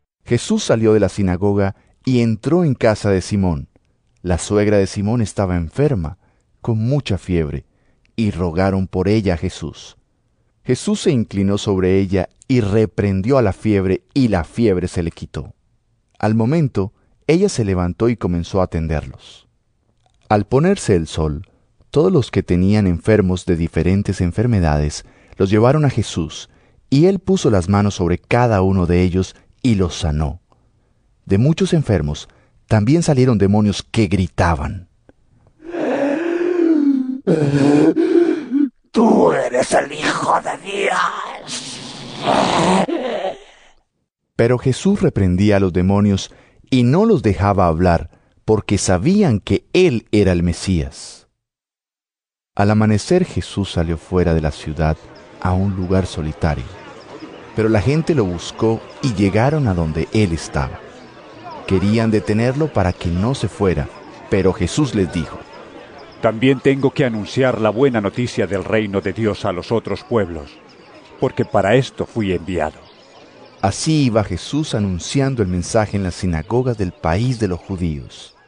Lc 4 38-44 EVANGELIO EN AUDIO La suegra de Pedro simboliza la opresión machista, religiosa y política que padecían las mujeres en tiempos de Jesús.